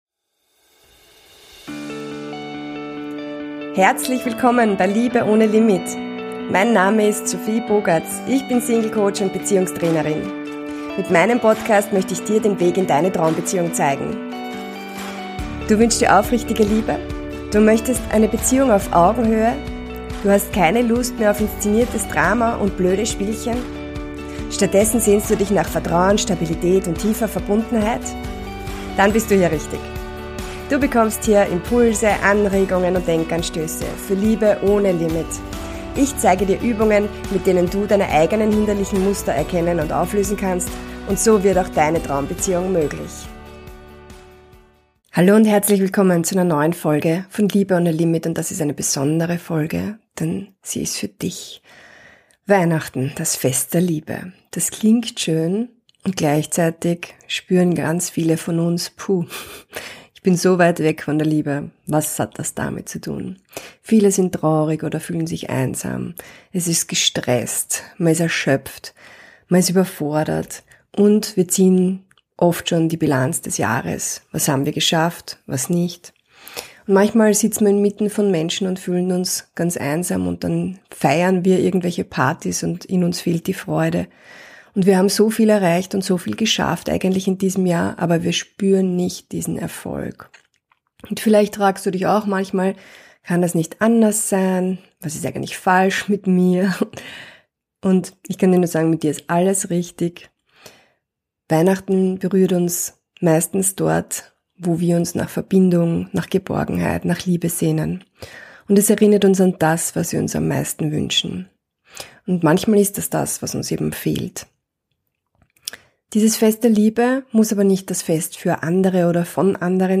Mit einer geführten Meditation und Reflexionsfragen schenkt dir diese Folge einen Raum für echte Verbindung: mit dir selbst.